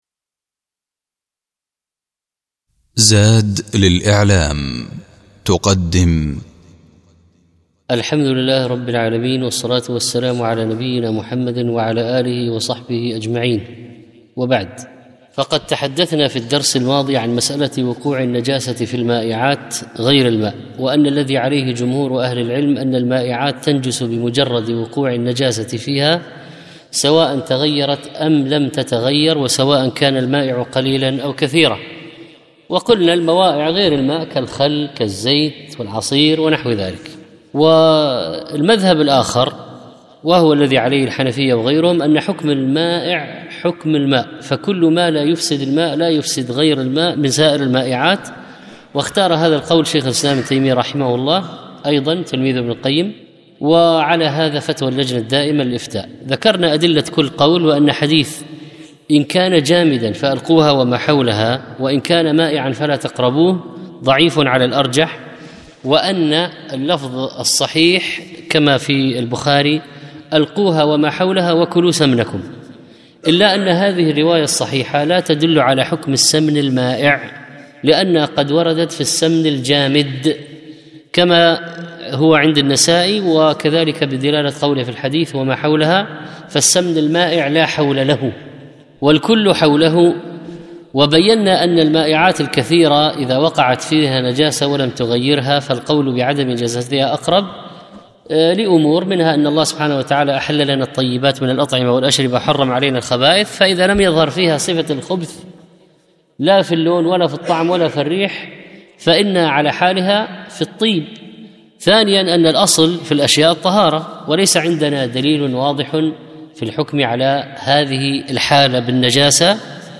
أحكام المياه - الدرس الخامس